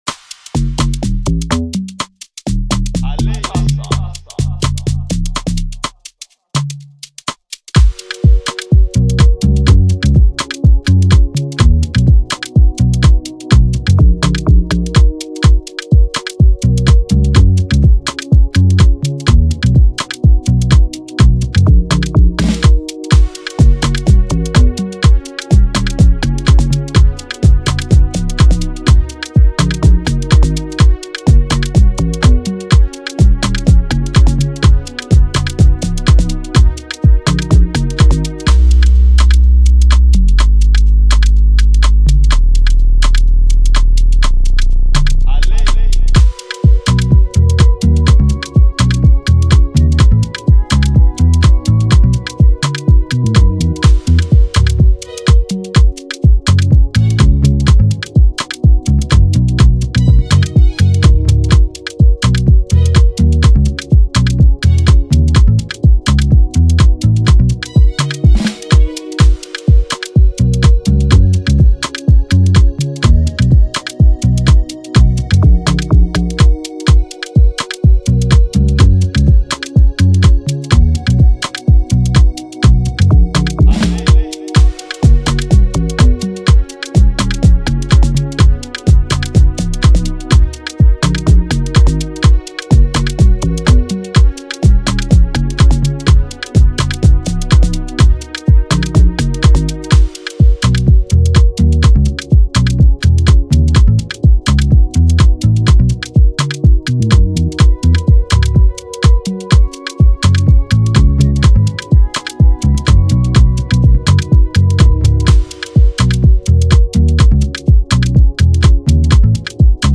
afrobeat mp3 beat